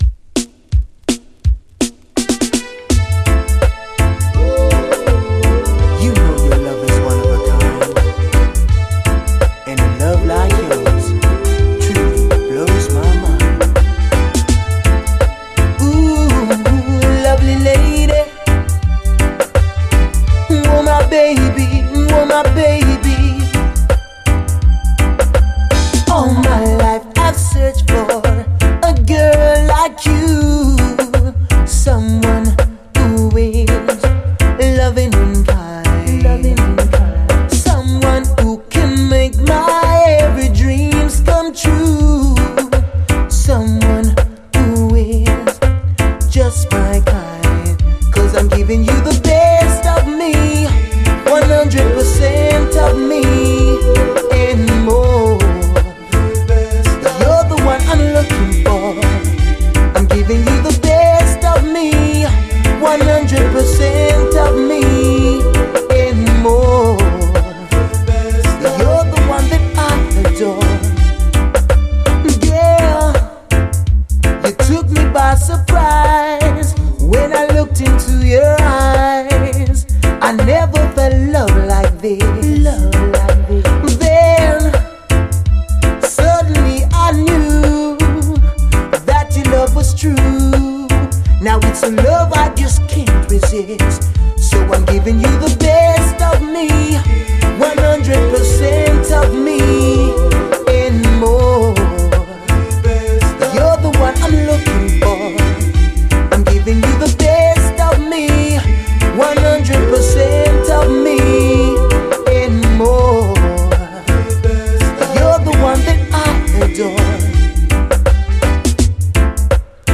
REGGAE
美メロ輝くマイナー90’S UKラヴァーズ！